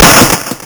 cop_shoot.ogg